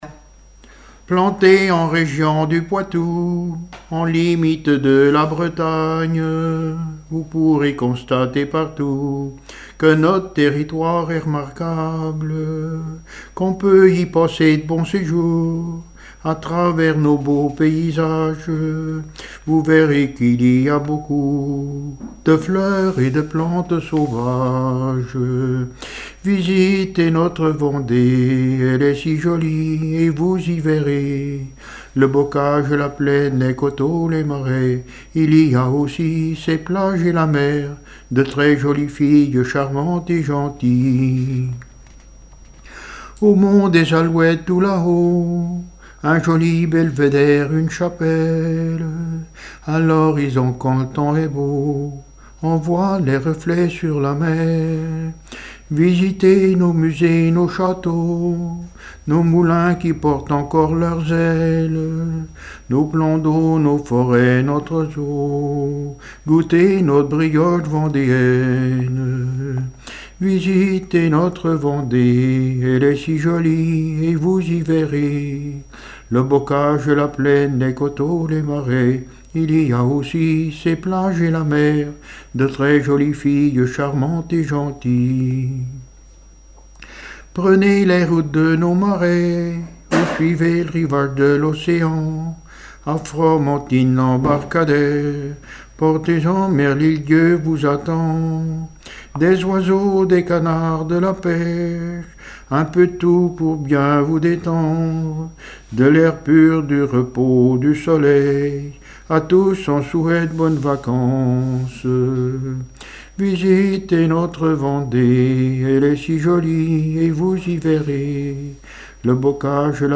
Témoignages et chansons
Pièce musicale inédite